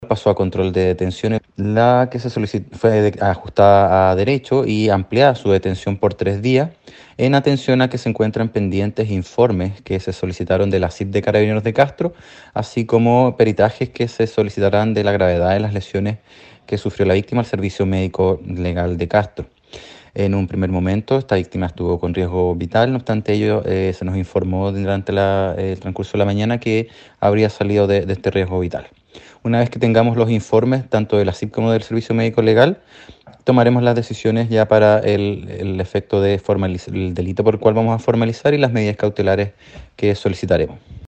01-FISCAL-LUIS-BARRIA-VIF-CASTRO-2.mp3